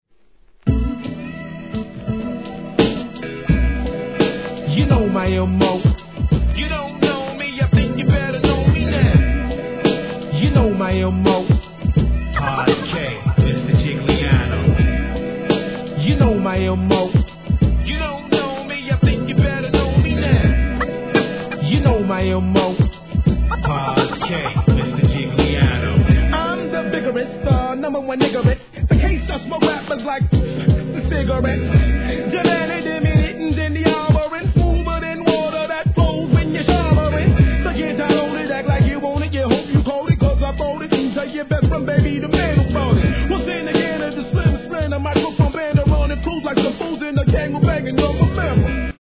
HIP HOP/R&B
ファットなキックにコスリがはまるフックもCOOL!!